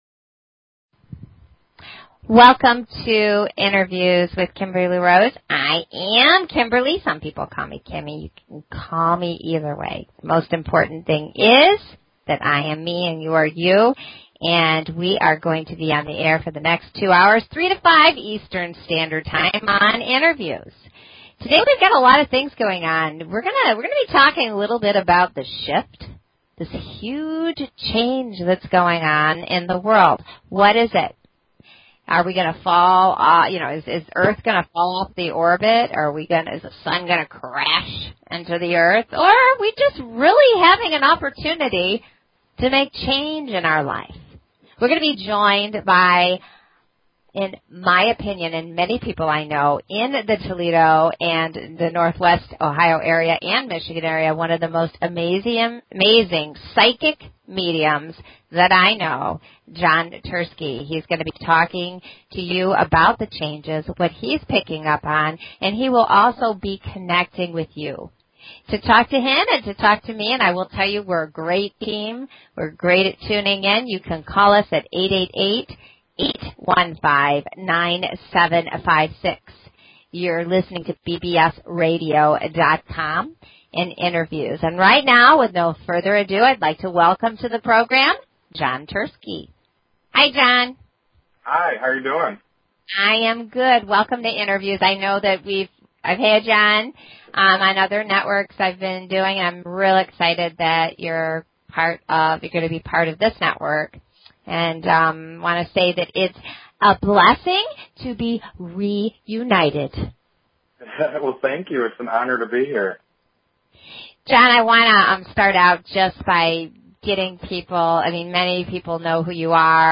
Talk Show Episode
Talk Show